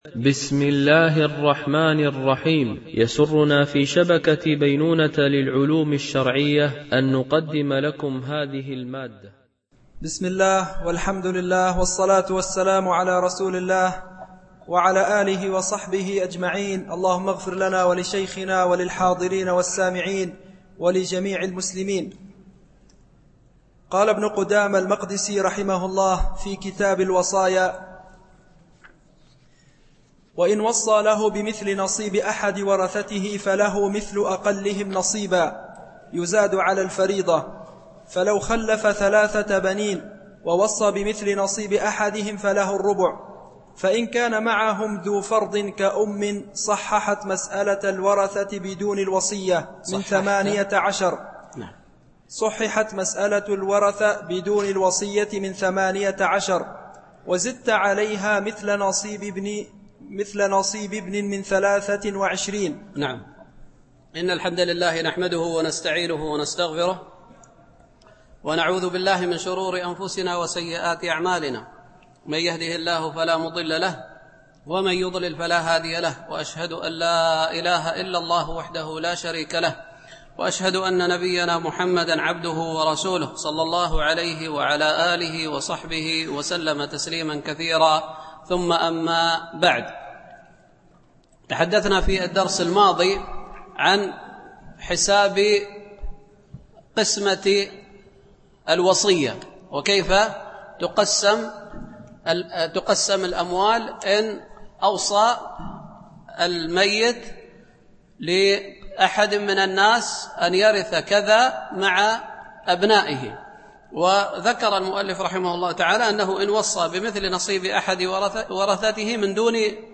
شرح كتاب الوصايا من عمدة الفقه ـ الدرس 3
دورة الإمام مالك العلمية السادسة، بدبي